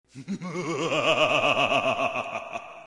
Download Evil Laugh sound effect for free.
Evil Laugh